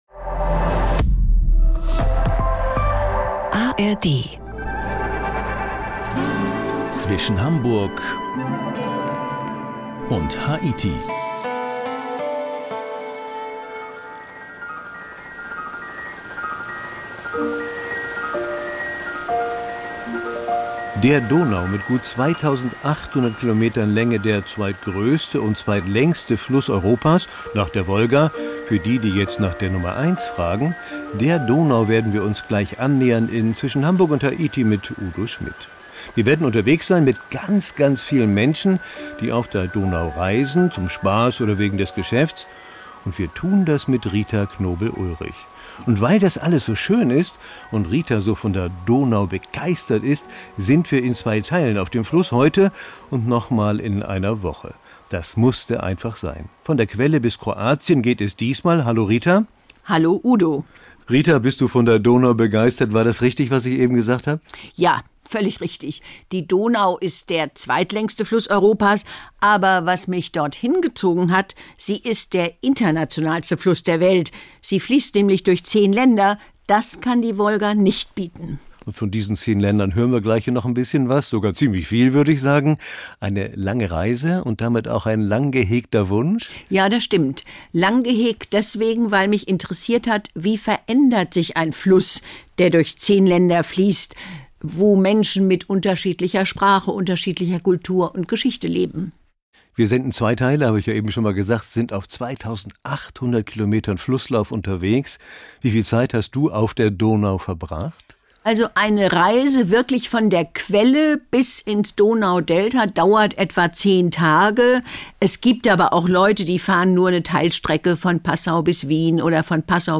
Mit dem Mikrofon rund um die Welt.